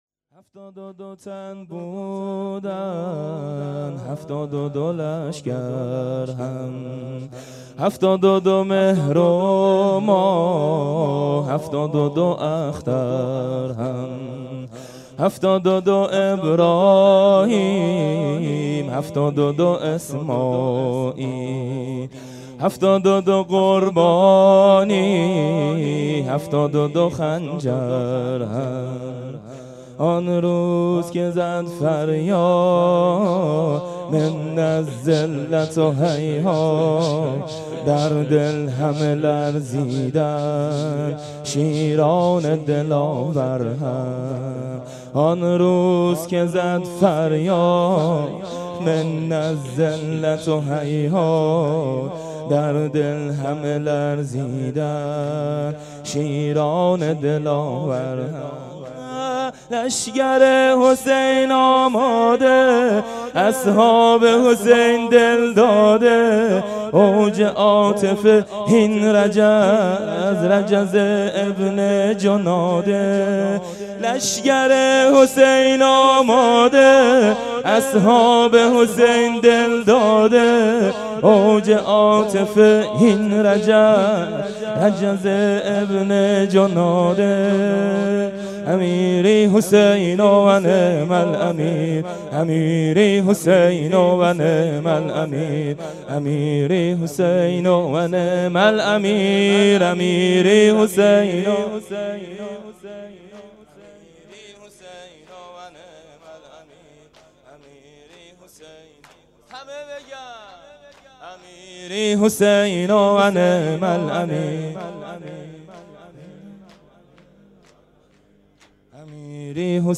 حسینیه بنی فاطمه(س)بیت الشهدا
محرم 97